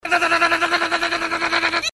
Morse Code SOS Sound Effect
Mã Morse Hiệu ứng âm thanh SOS